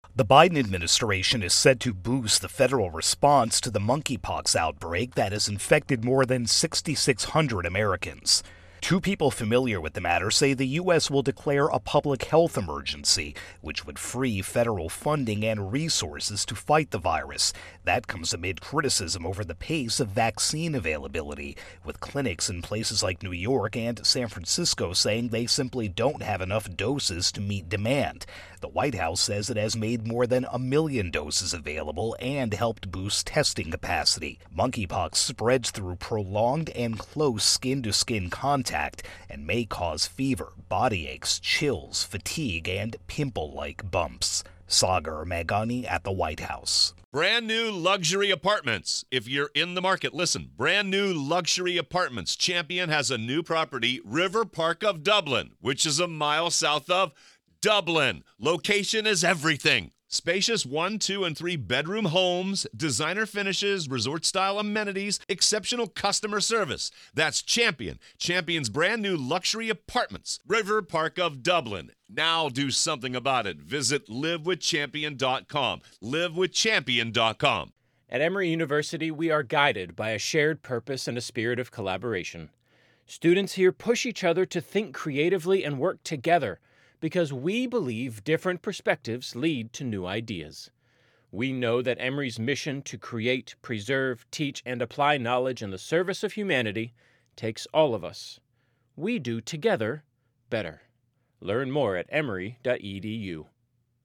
reports on Monkeypox.